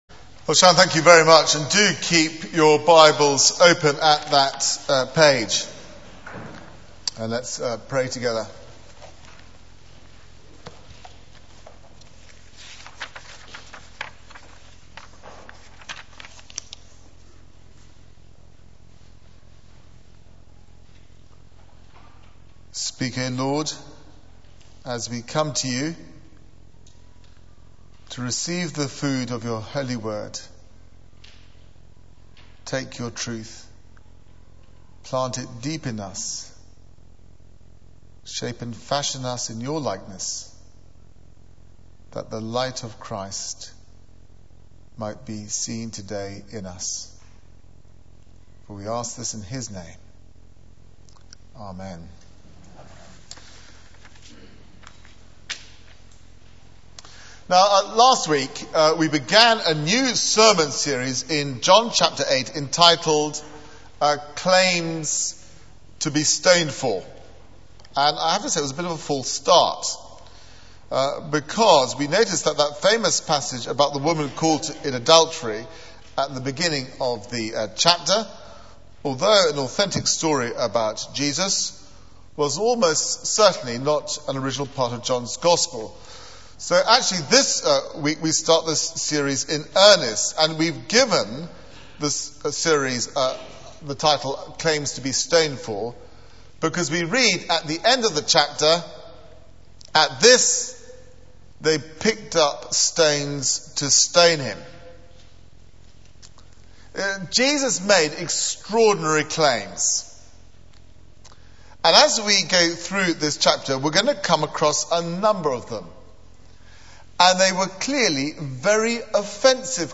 Media for 9:15am Service on Sun 26th Apr 2009 09:15 Speaker: Passage: John 8:12-20 Series: Claims to be Stoned For Theme: Light of the World Sermon Search the media library There are recordings here going back several years.